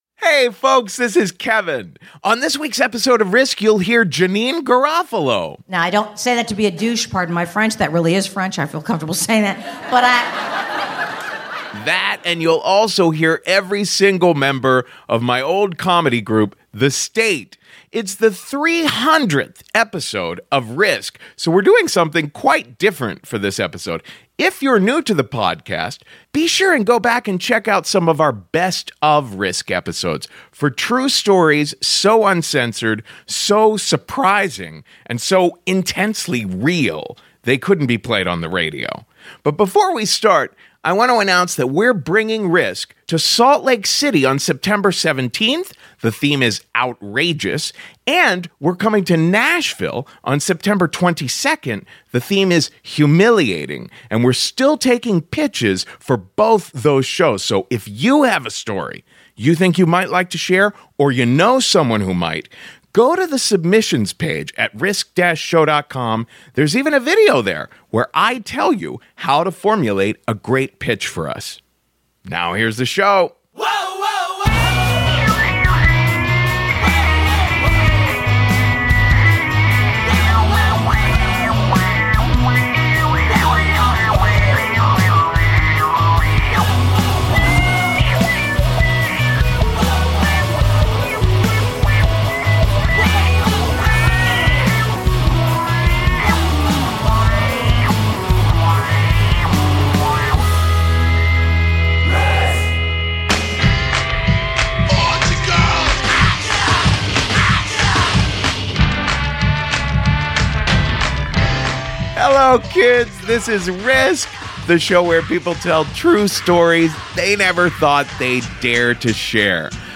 On the 300th episode of RISK!, all 11 members of the comedy troupe The State recall working and playing together in the 90s.
With special guest Janeane Garofalo.